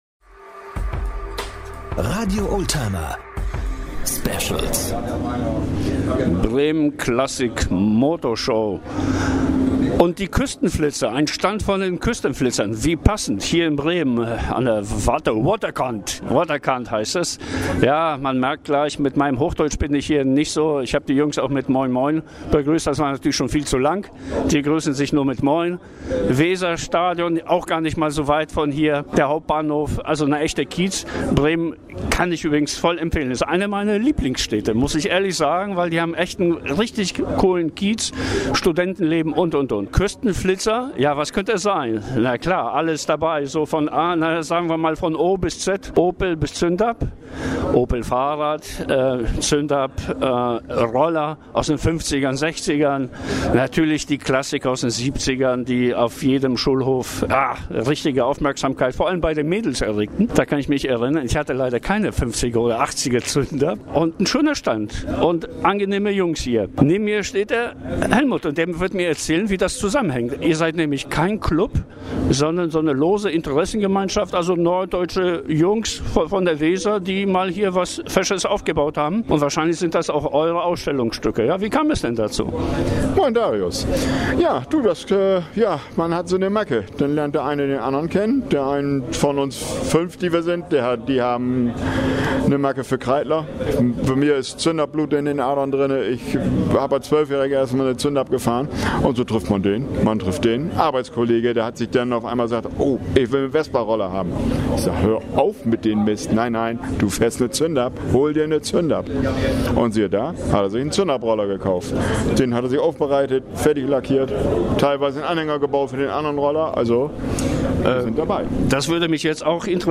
50-Kubik-Virus an der Waterkant: Küstenflitzer - Bremen Classic Motorshow - Interviews & Reportagen | RADIO OLDTIMER